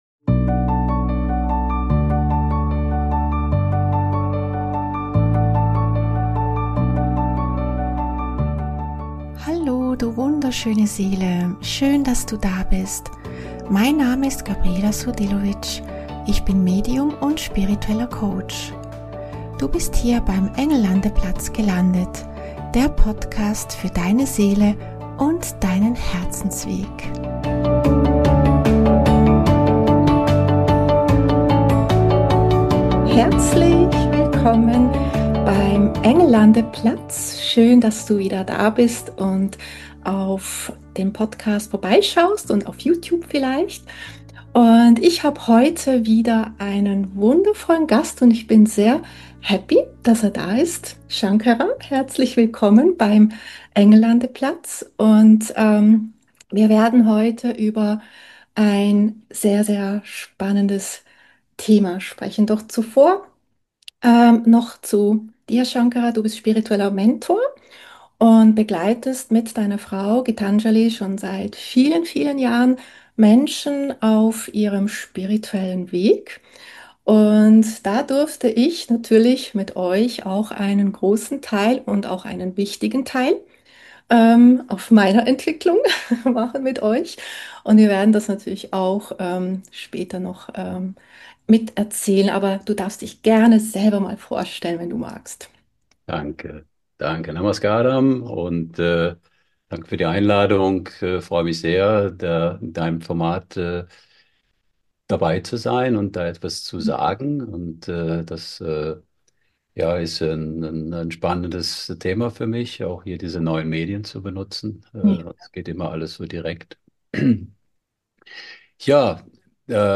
In dieser Folge habe ich einen wundervollen Gast zu Besuch beim Engel Landeplatz.